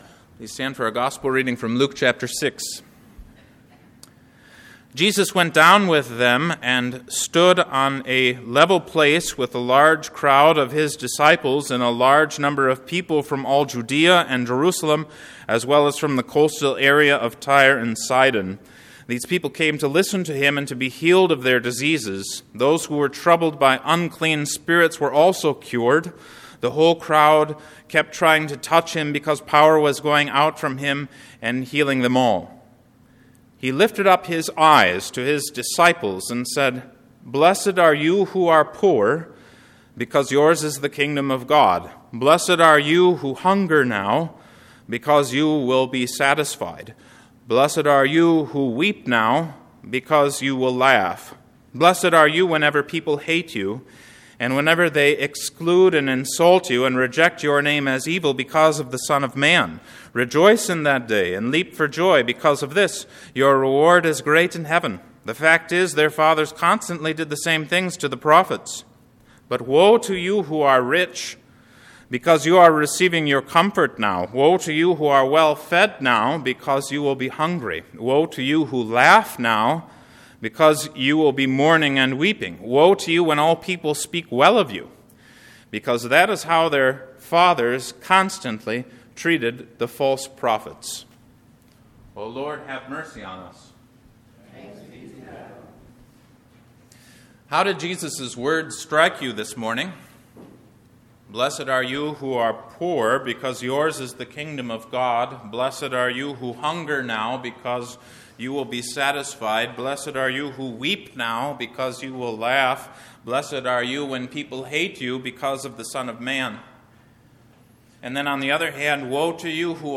220213 Sermon on Luke 6:17-26 (Epiphany 6C) February 13, 2022